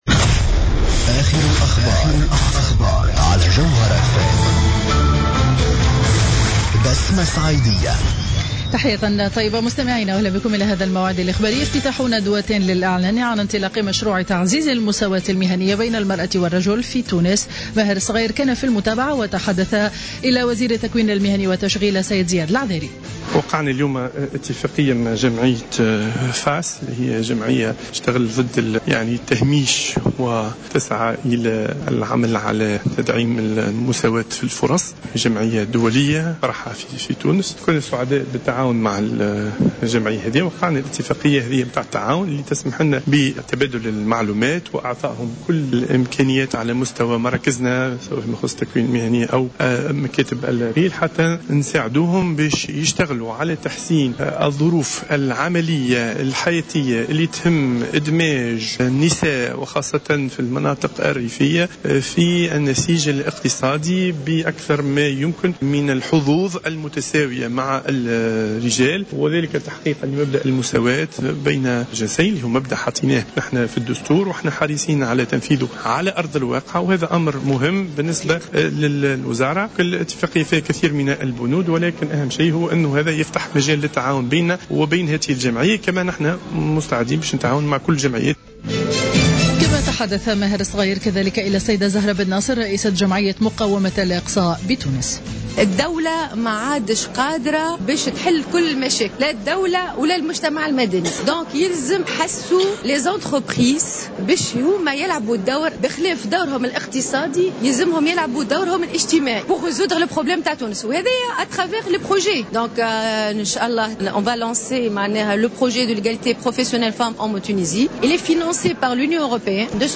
نشرة أخبار منتصف النهار ليوم الاربعاء 11 مارس 2015